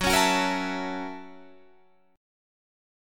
Listen to F#7#9 strummed